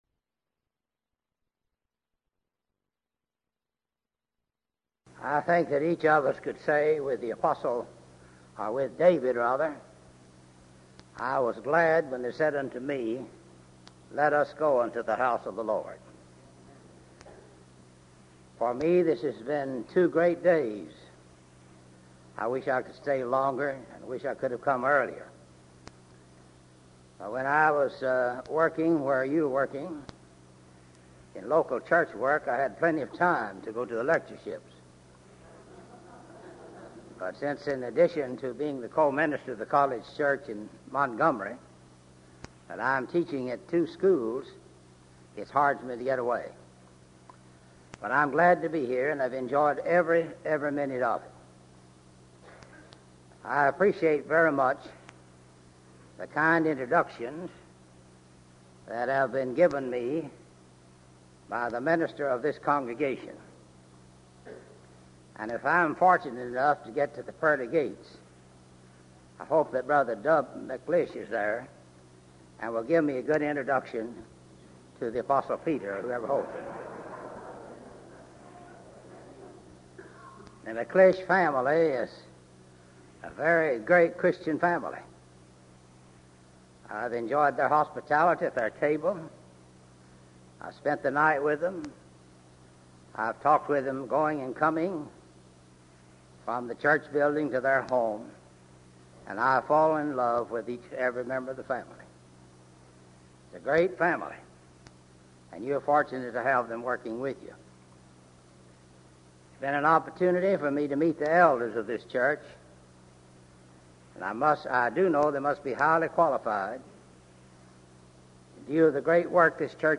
Event: 1984 Denton Lectures
lecture